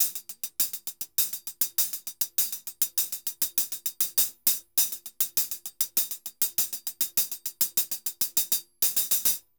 HH_Baion 100_1.wav